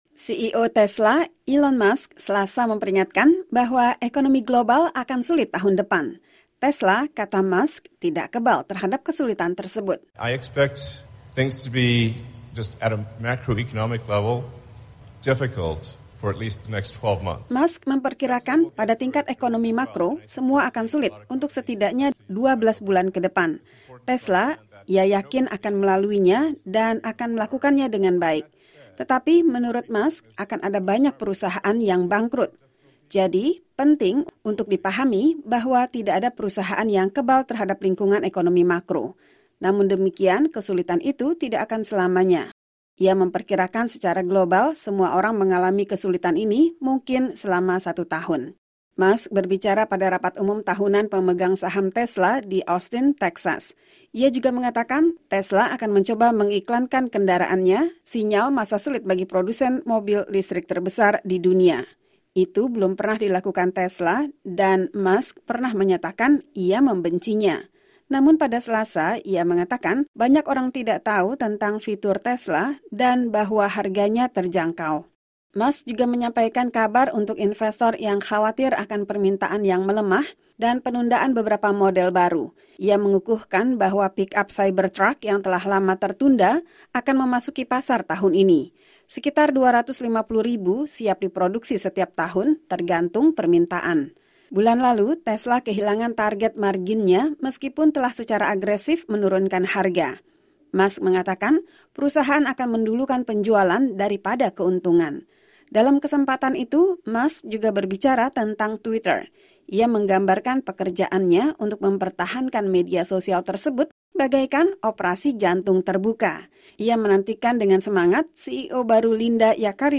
Musk berbicara pada rapat umum tahunan pemegang saham Tesla di Texas.